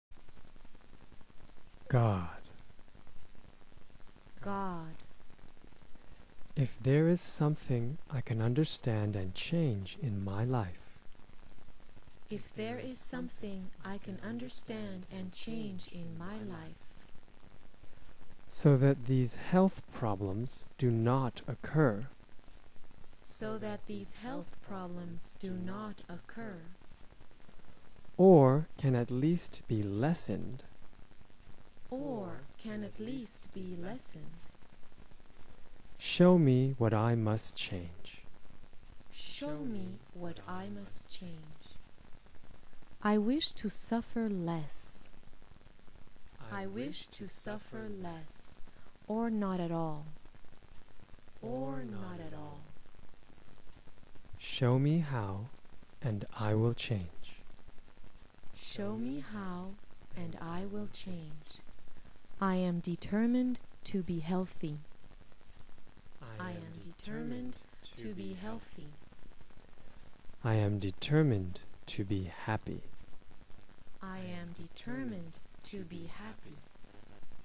The following prayers are recorded in a "call and response" format : we speak, then you can repeat.
Prayer for Change.wma